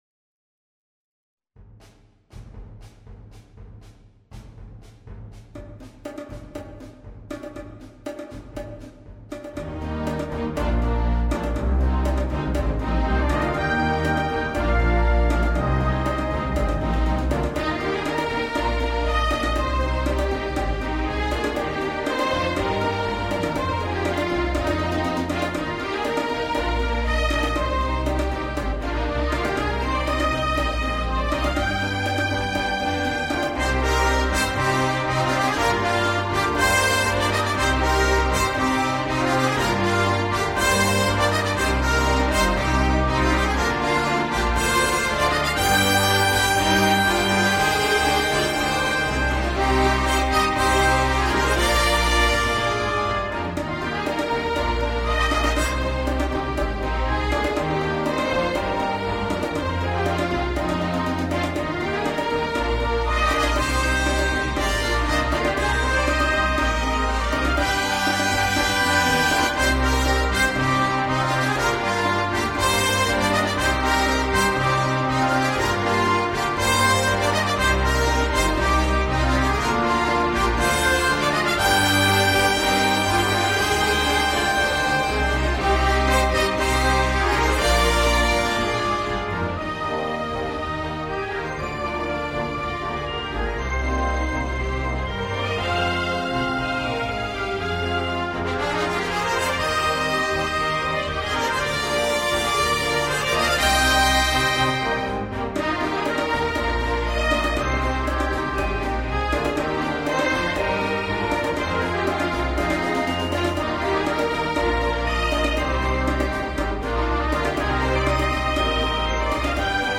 A light orchestral composition with a Calypso feel
The MP3 was recorded with NotePerformer 3.
Folk and World